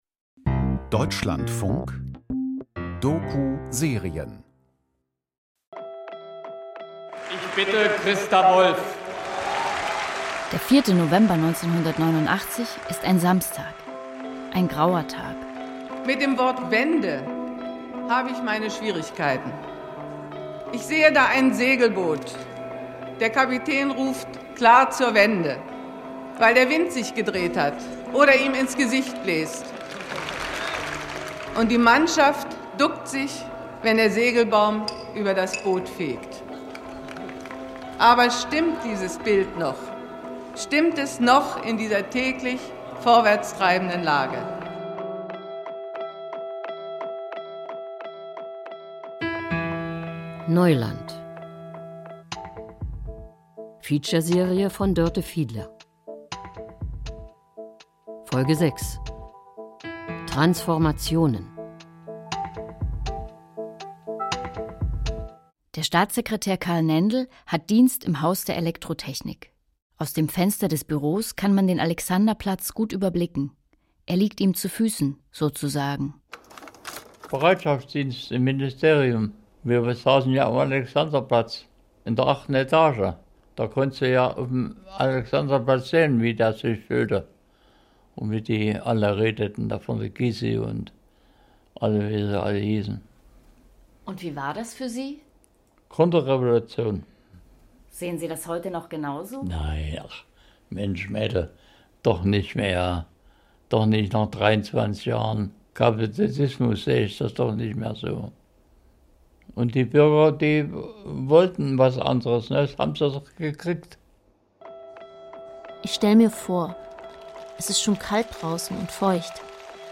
Satire im DDR-Rundfunk - Ein Spiegel für die West-Medien